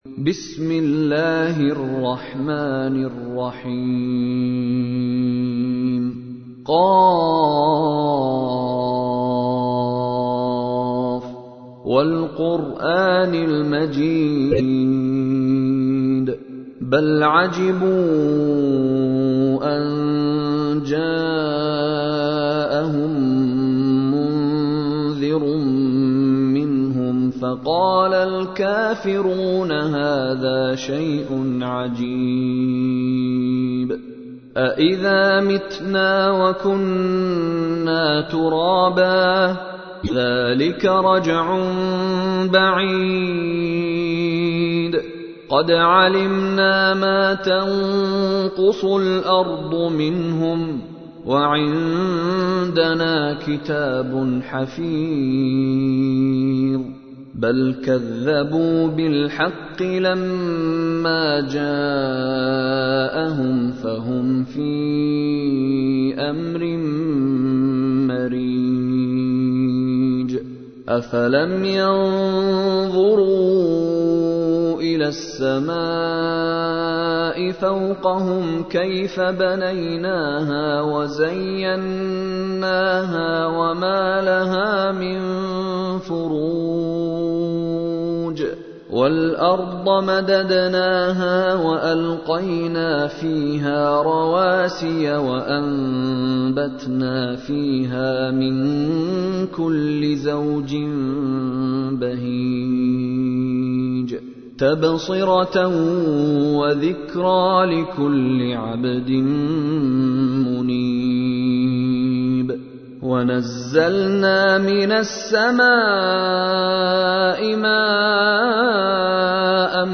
تحميل : 50. سورة ق / القارئ مشاري راشد العفاسي / القرآن الكريم / موقع يا حسين